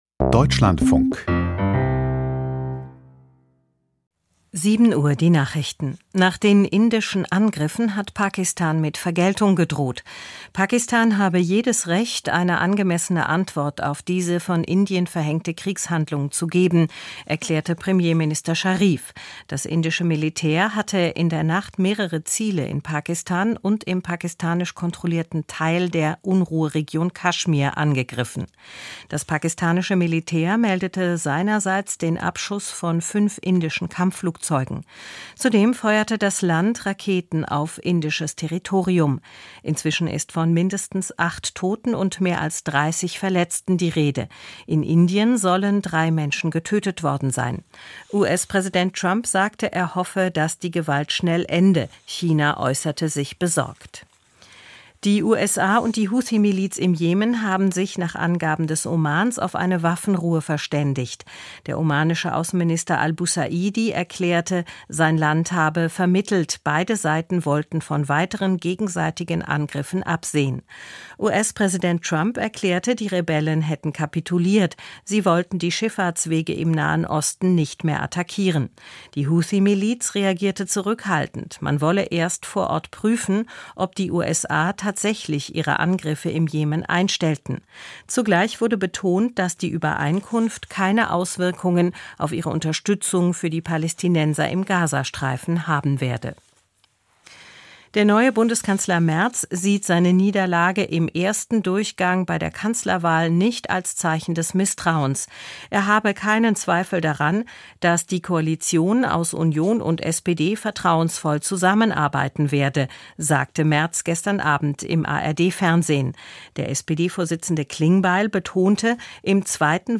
Die Deutschlandfunk-Nachrichten vom 07.05.2025, 07:00 Uhr